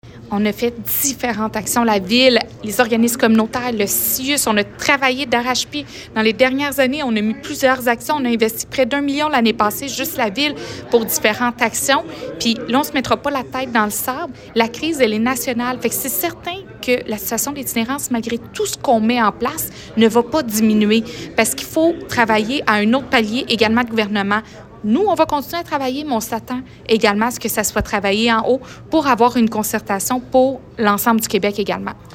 Julie Bourdon, mairesse de Granby.